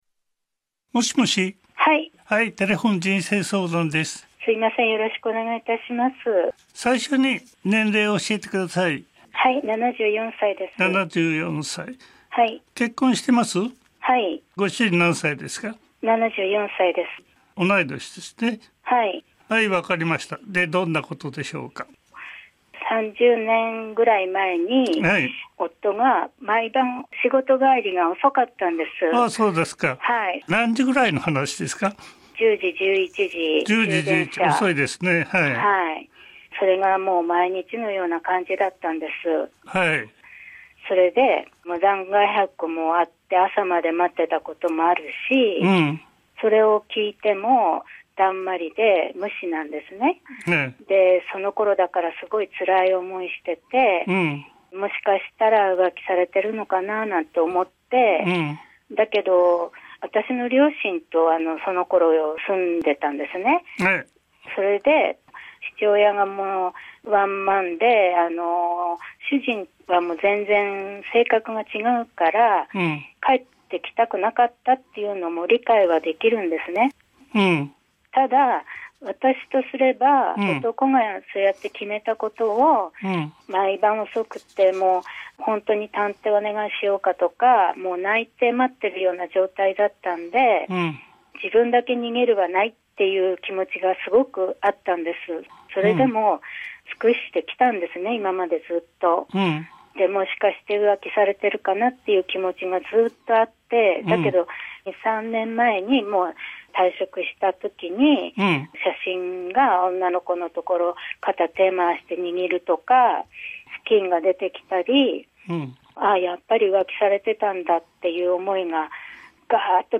30年前のスキンに疑惑は確信に。否定する夫を問い質す女を号泣させる加藤諦三